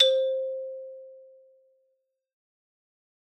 Xylo_Medium_C4_ff_01_far.wav